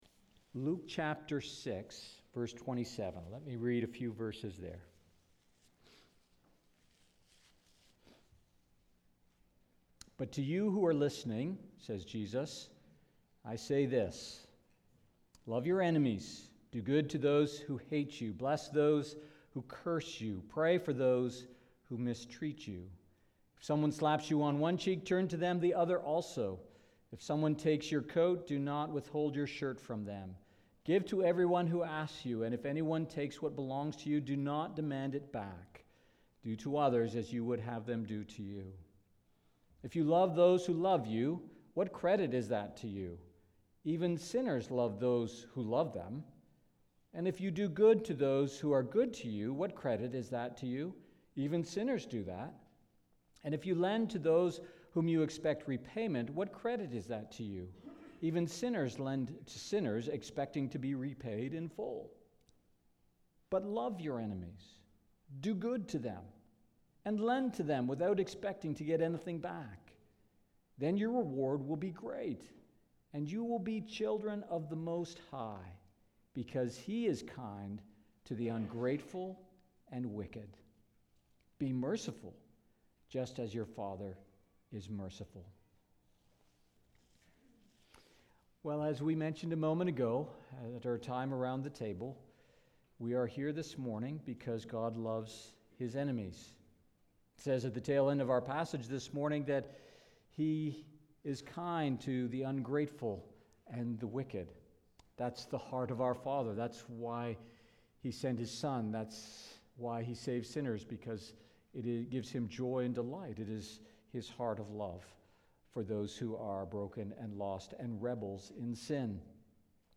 Sermons | Port Perry Baptist